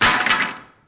1 channel
dropwood.mp3